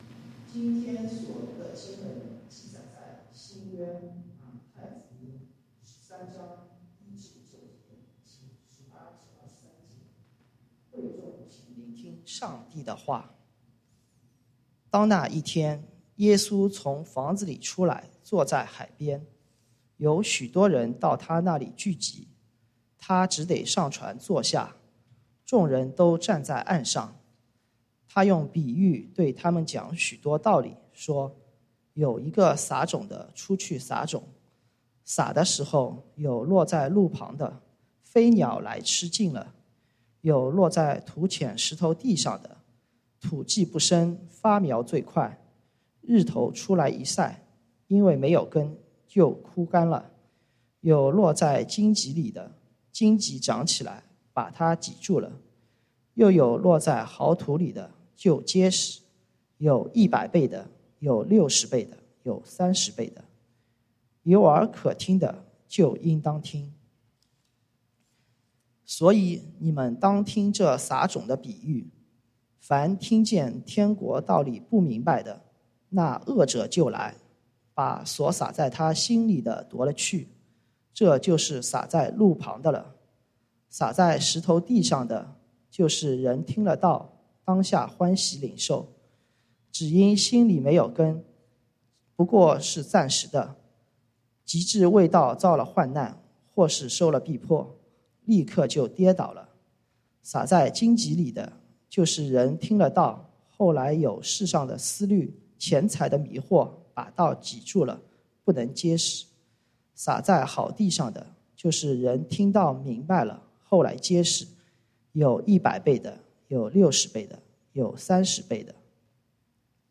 講道經文：《馬太福音》Matthew 13:1-9，18-23